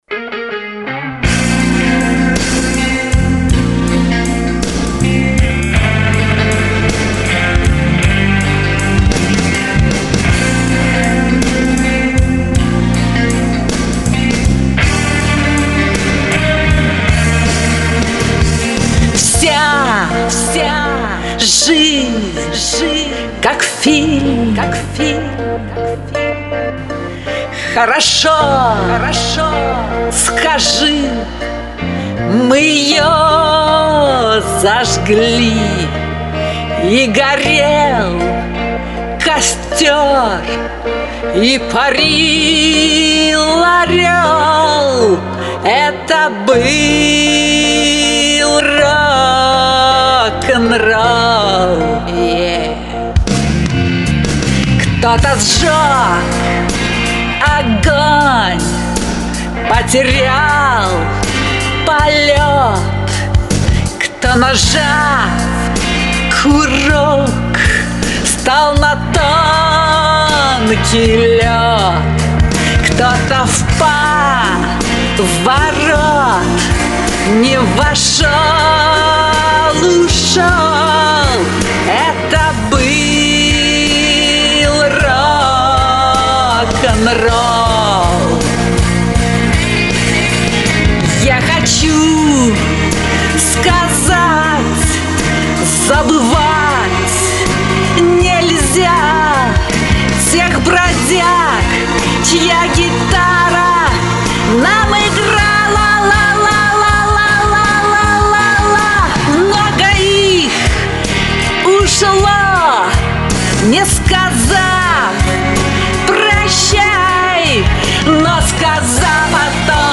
Иногда варьирую степень эха.
четкое сведение, выверенное пение.